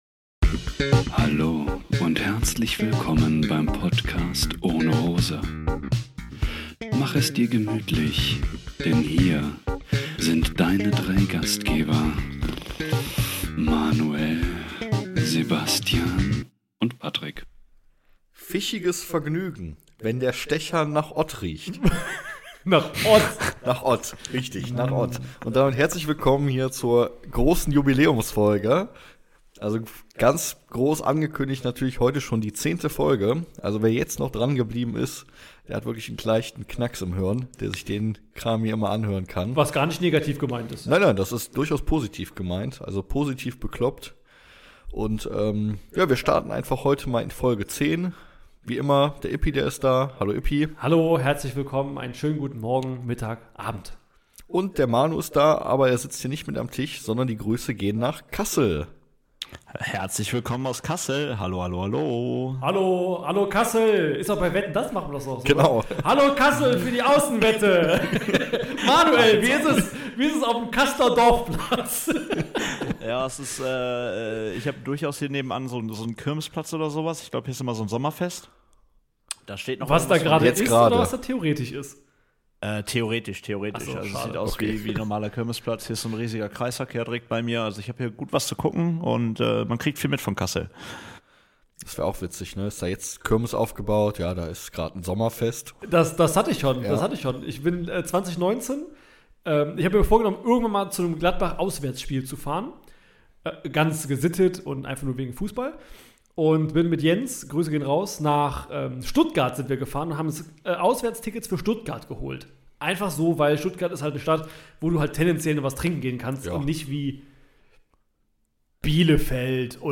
Wir haben es getan! Diese Folge starten wir unsere Gesangskarriere und probieren uns als Boyband.